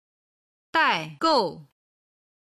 今日の振り返り！中国語発声
01-daigou.mp3